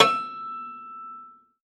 53v-pno12-E4.wav